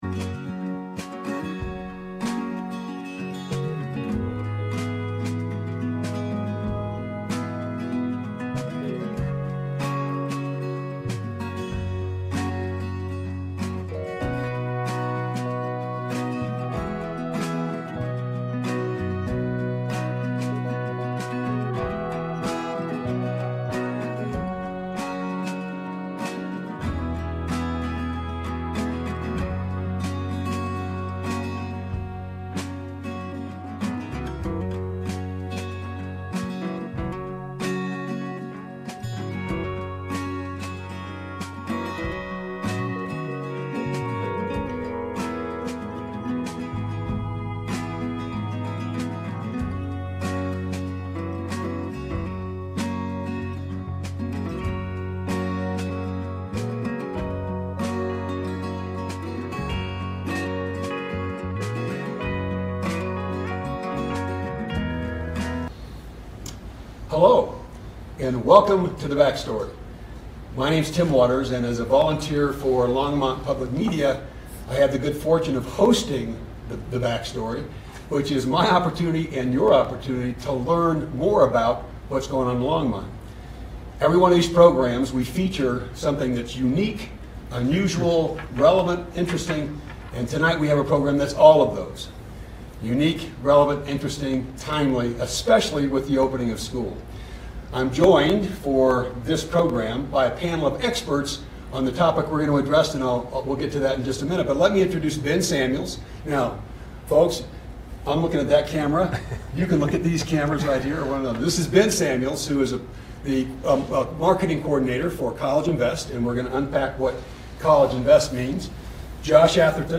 The Backstory on Longmont's 529 Jump Program - Live at Longmont Public Media